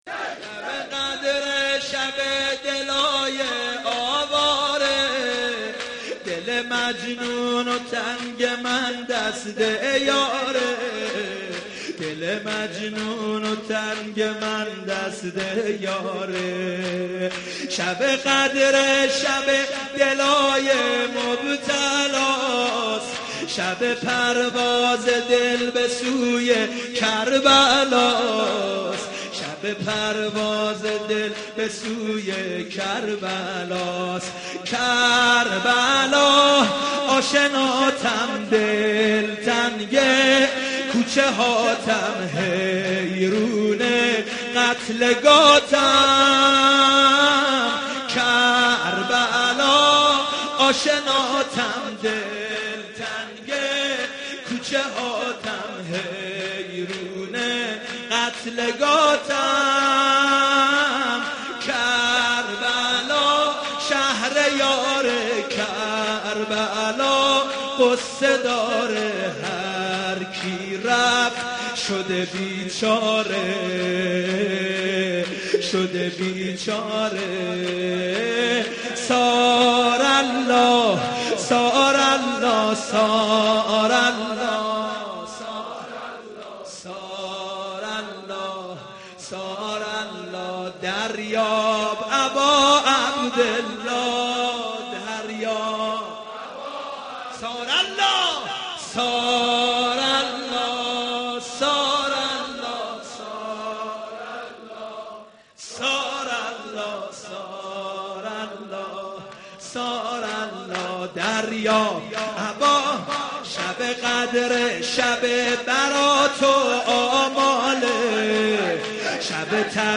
رمضان 89 - سینه زنی 3